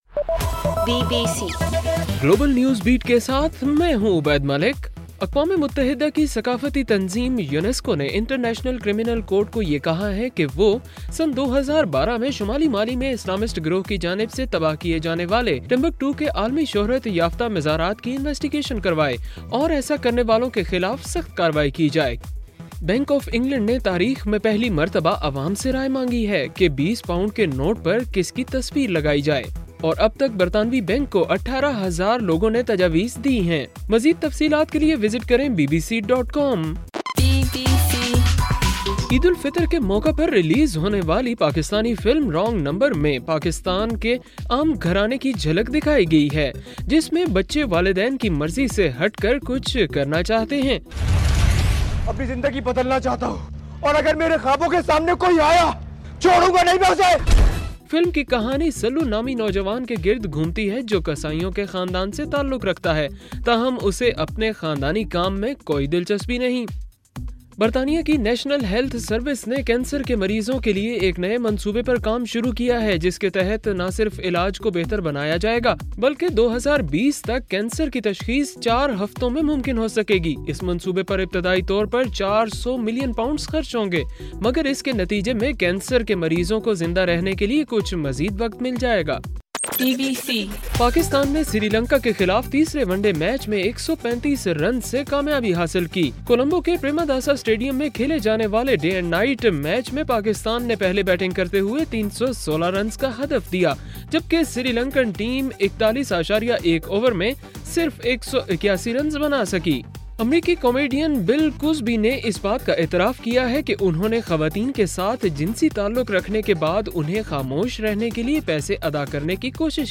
جولائی 20: صبح 1 بجے کا گلوبل نیوز بیٹ بُلیٹن